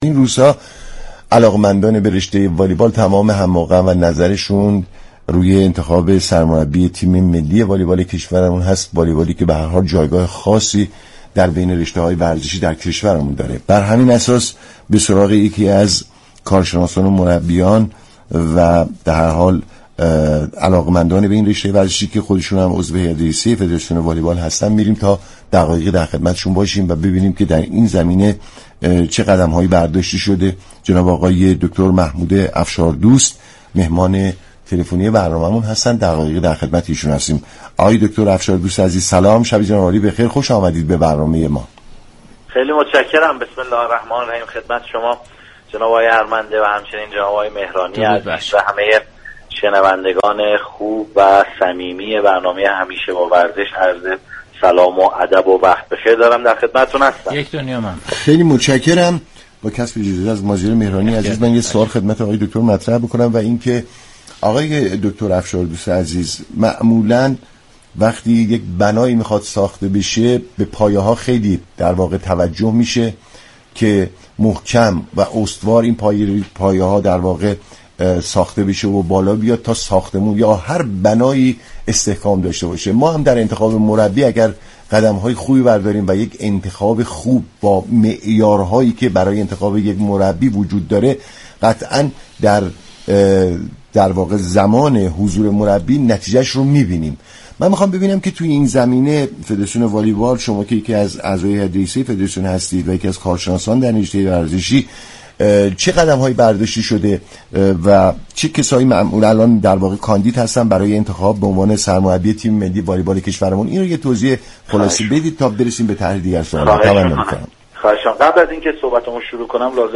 شما مخاطب محترم می توانید از طریق فایل صوتی پیوست شنونده ادامه این گفتگو باشید.